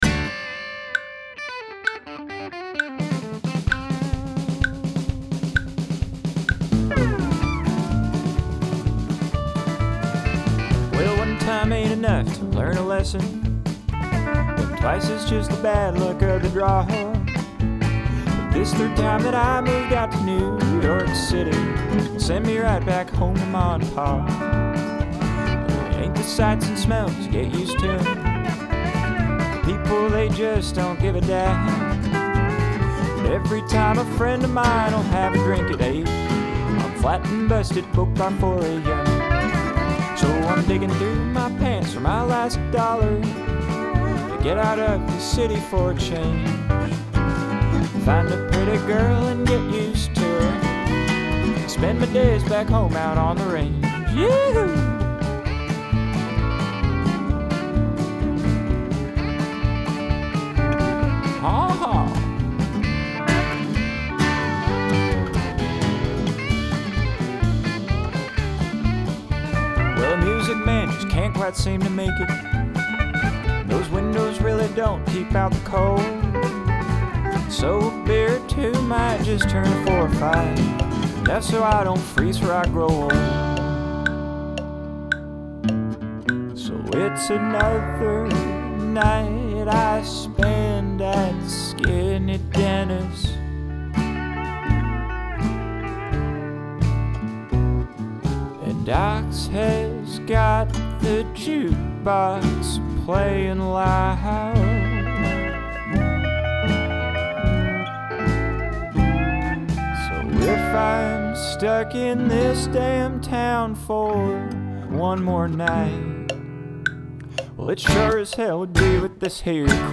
So check out a near-final version of the album’s closer:
the pedal steel is a little hot … but who doesn’t love a little extra pedal steel.